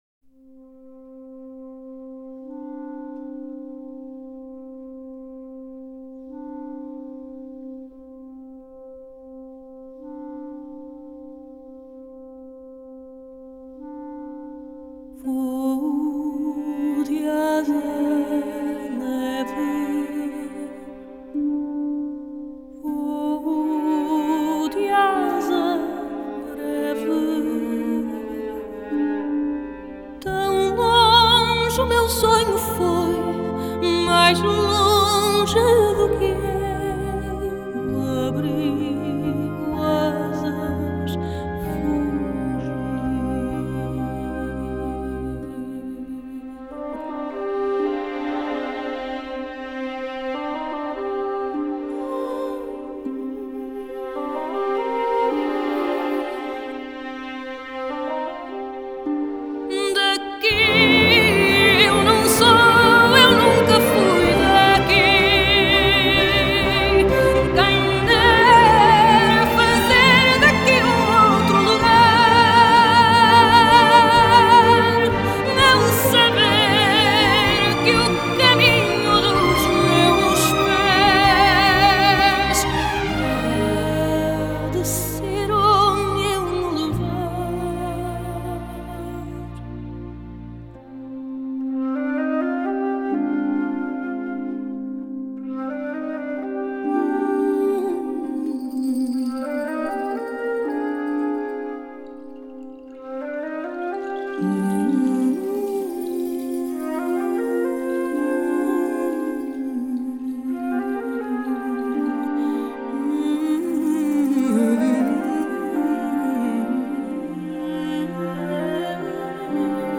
Genre: Score
DSD-layer: Stereo, 5.1 Multichannel.